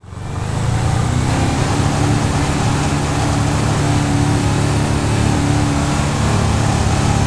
Index of /server/sound/vehicles/tdmcars/gtav/mesa3
rev.wav